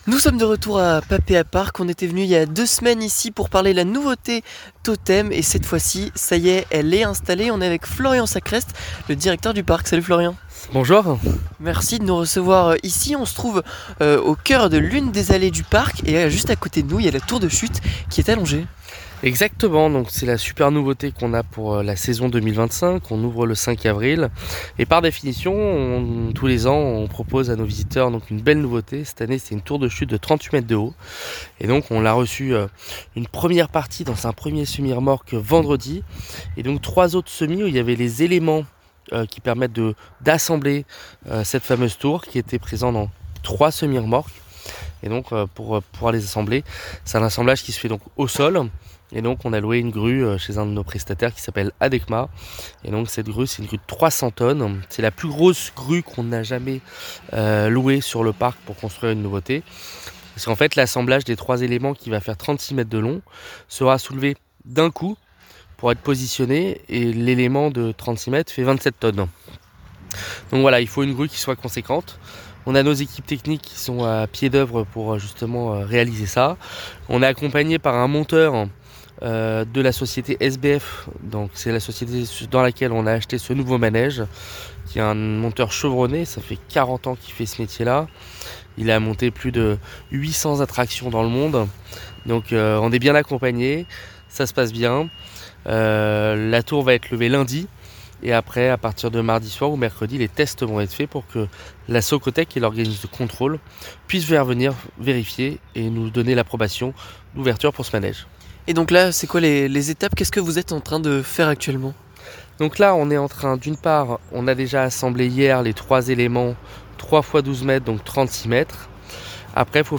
« Totem », une tour de chute de 38 mètres de haut, avec une nacelle rotative, va prendre place dans le passage western. Nous nous sommes faufilés dans les allées du parc lors du montage de la tour.